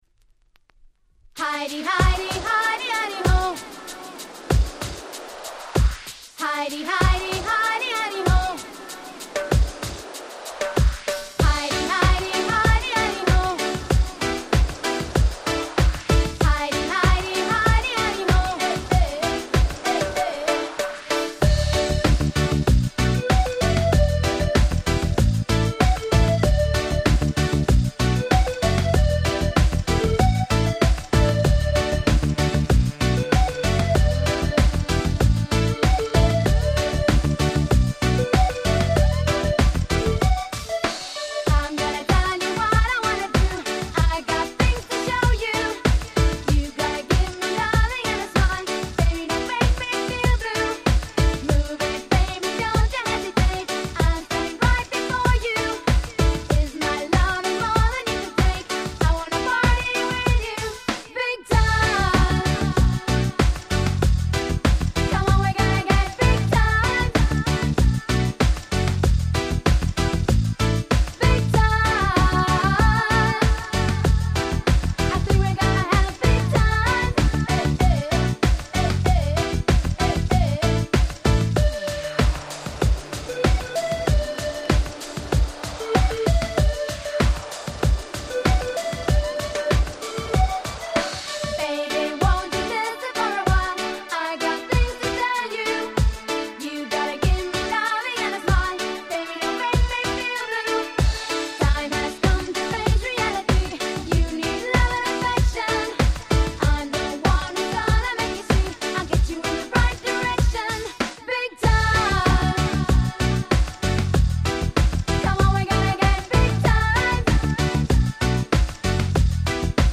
【Media】Vinyl 12'' Single (2nd Press)
※試聴ファイルは別の盤から録音してございます。